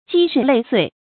積日累歲 注音： ㄐㄧ ㄖㄧˋ ㄌㄟˇ ㄙㄨㄟˋ 讀音讀法： 意思解釋： 形容時間長久。